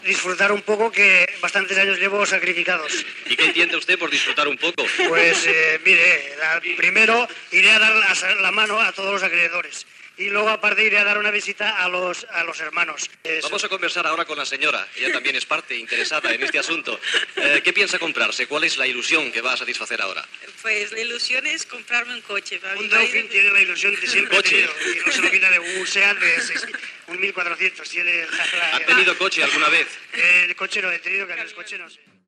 Declaracions, a Barcelona, d'un matrimoni afortunat que té un dècim del primer premi de la rifa de Nadal
Informatiu